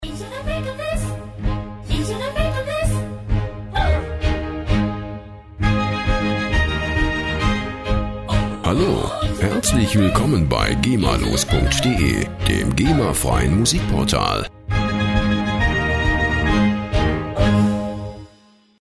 Werbemusik Loops für Ihr Unternehmen
Musikstil: Comedy Klassik
Tempo: 130 bpm